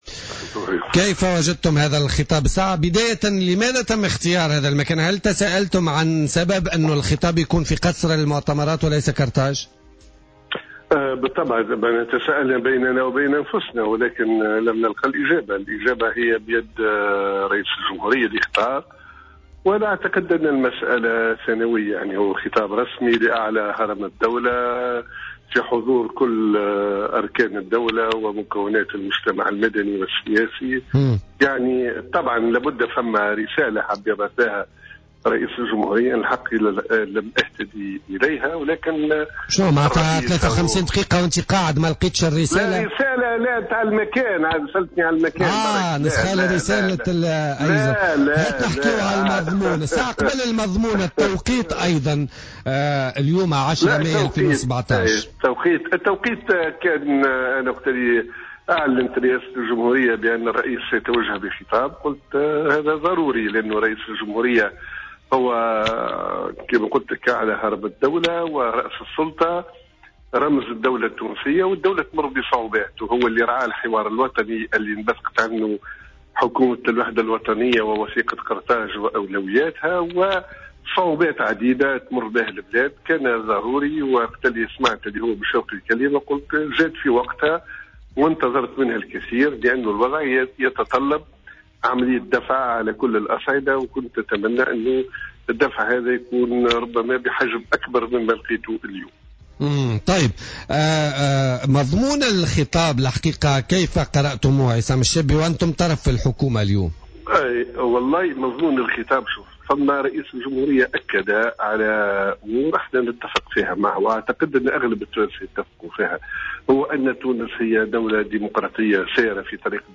اعتبر عصام الشابي، أمين عام الحزب الجمهوري، في تصريح للجوهرة أف أم، اليوم الأربعاء، خلال حصة "بوليتيكا"، أن خطاب رئيس الجمهورية الباجي قائد السبسي لم يأت بالجديد، حيث لم يتعرض لعديد النقاط الهامة ذات الأولوية.